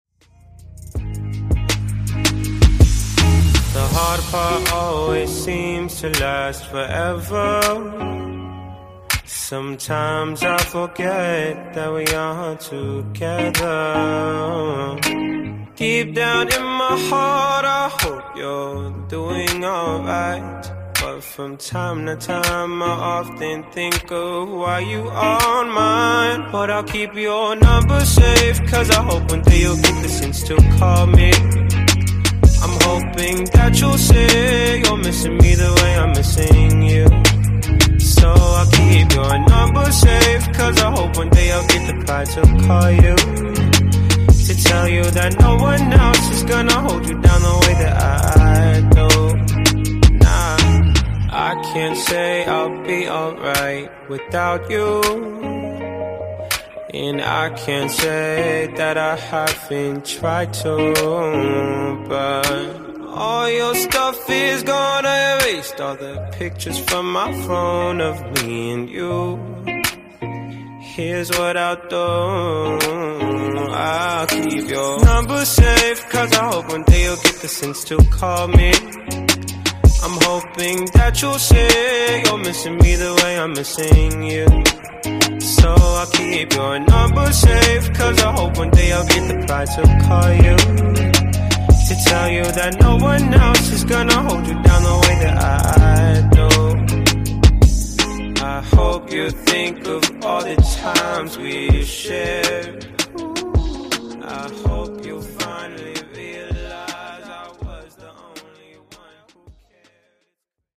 Genre: RE-DRUM Version: Clean BPM: 80 Time